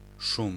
Ääntäminen
Ääntäminen Tuntematon aksentti: IPA: [ʂum] Haettu sana löytyi näillä lähdekielillä: puola Käännös Konteksti Ääninäyte Substantiivit 1. sough 2. noise teknologia US UK 3. hype Suku: m .